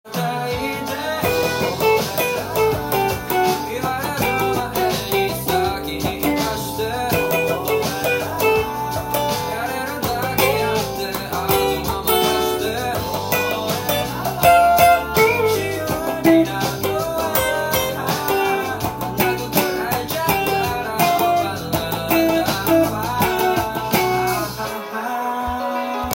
音源にあわせて譜面通り弾いてみました
曲調もおしゃれ洋楽風です。
サビのkeyがEmまたはGになるので
カッティング奏法で弾いていきます。